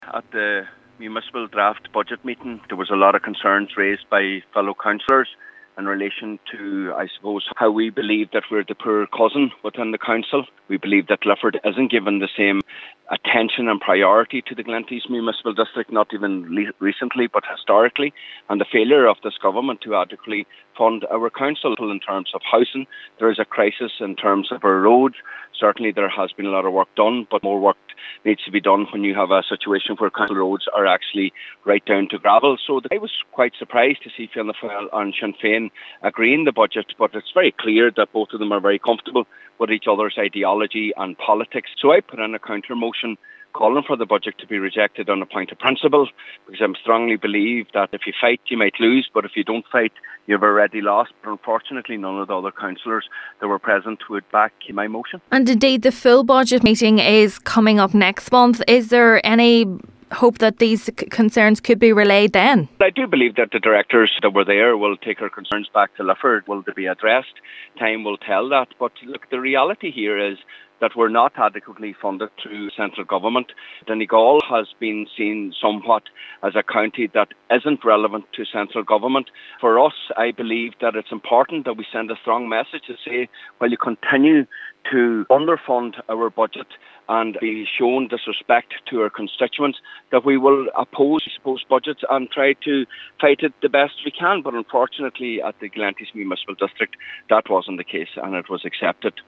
Councillor MacGiolla Easbuig believes all municipal districts in the county should be looked upon as equal: